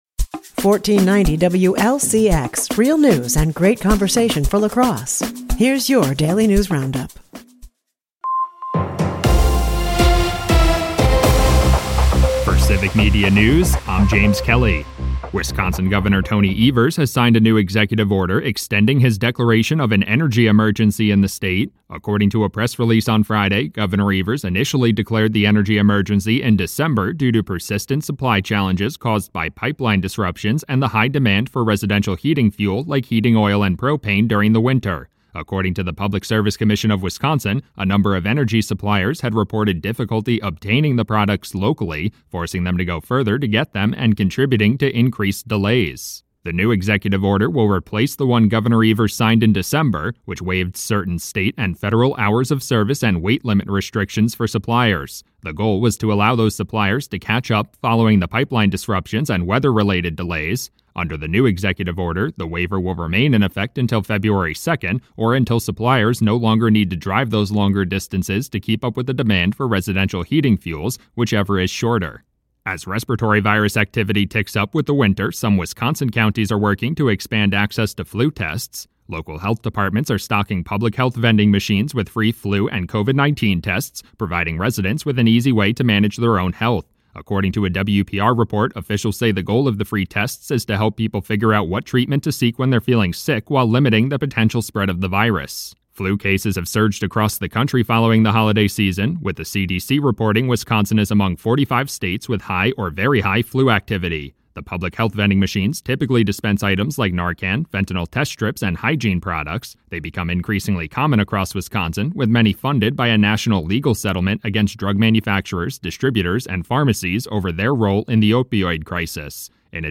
WLCX Monday News Roundup - Civic Media
wlcx news